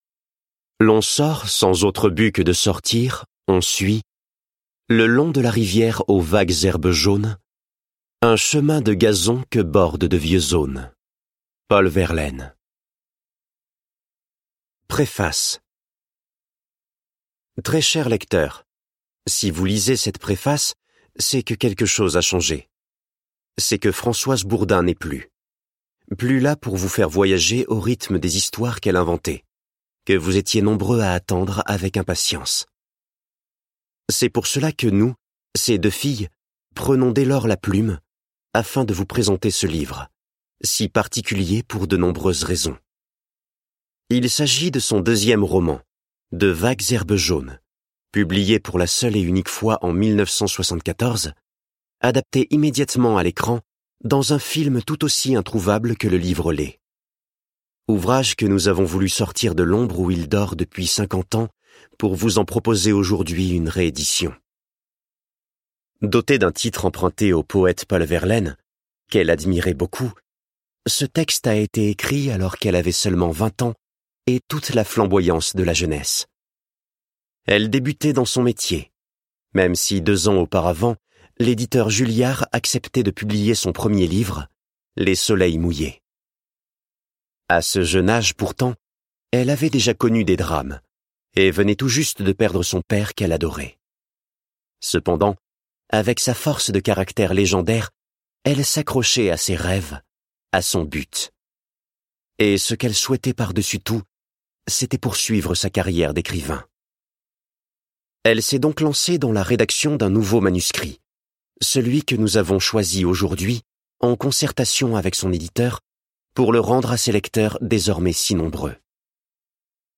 je découvre un extrait - De vagues herbes jaunes de Françoise BOURDIN